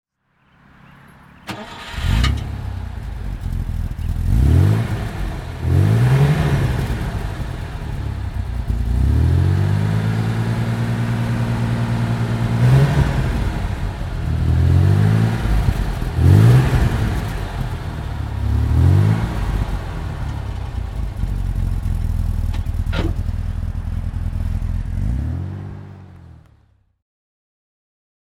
Mercedes-Benz 300 S Roadster (1953) - Starten und Leerlauf